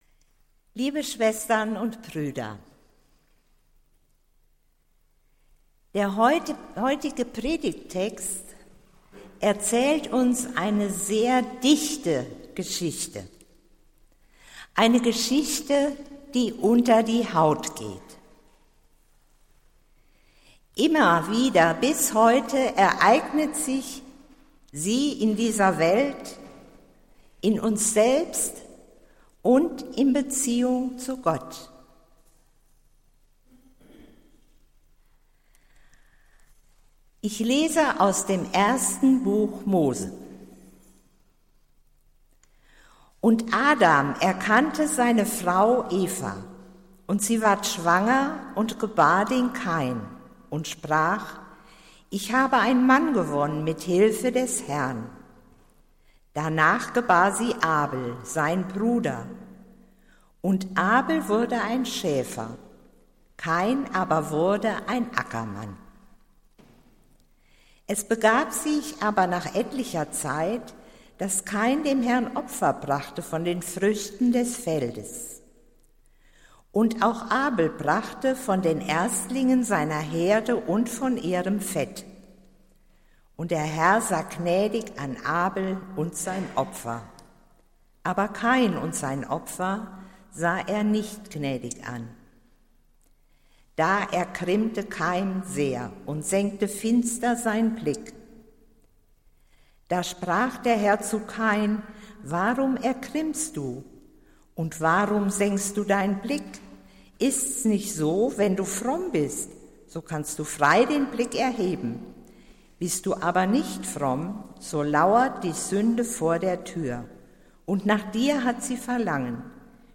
Predigt des Gottesdienstes aus der Zionskirche vom Sonntag, den 29.08.2021